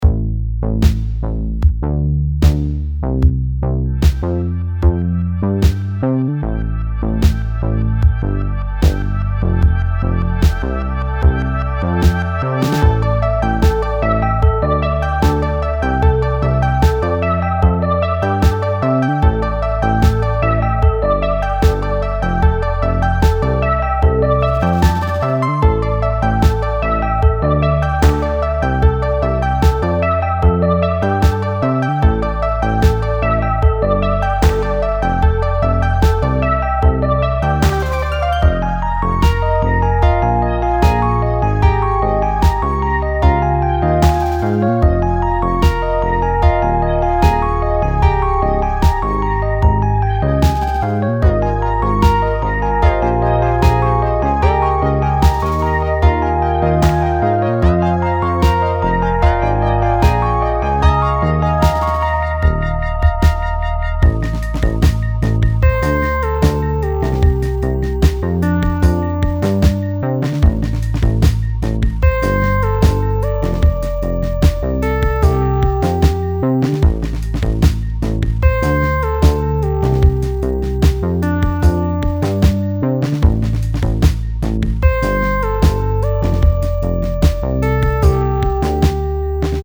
clicks.mp3